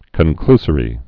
(kən-klsə-rē)